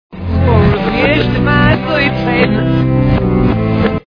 back masked